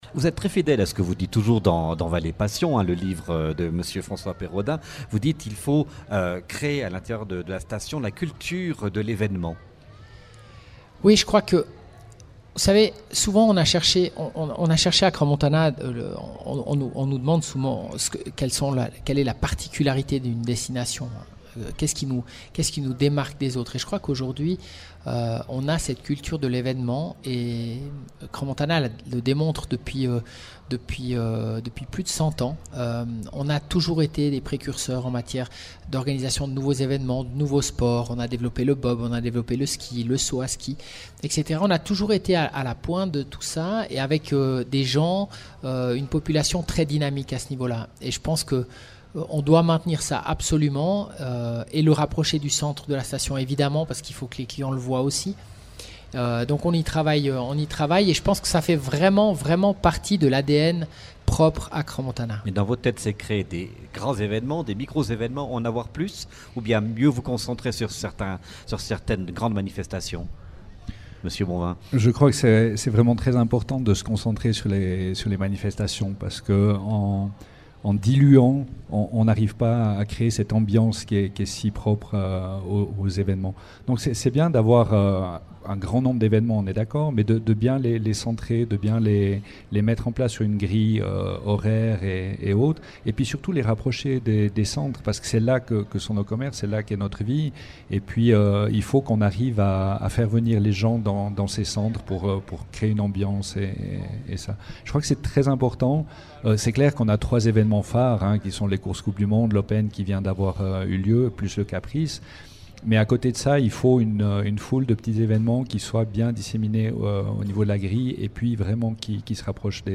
Radio Crans-Montana était en direct du marché à Crans-Montana le 31 juillet. L'occasion de rencontrer de nombreux acteurs de la station.